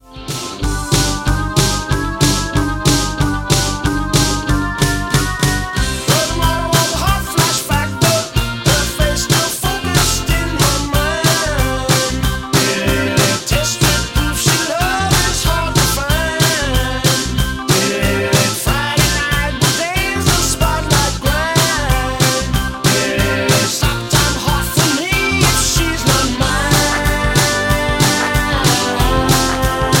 MPEG 1 Layer 3 (Stereo)
Backing track Karaoke
Pop, Rock, 1980s